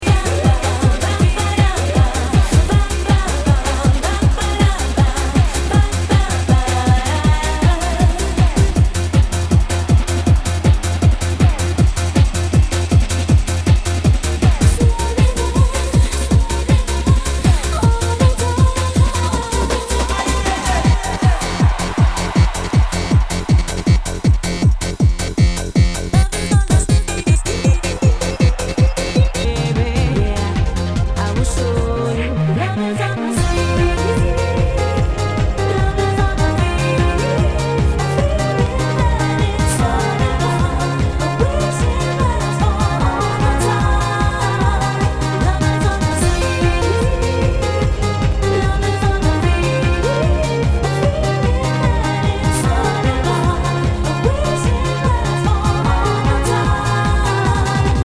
HOUSE/TECHNO/ELECTRO
ナイス！ユーロ・ヴォーカル・ハウス！
全体にチリノイズが入ります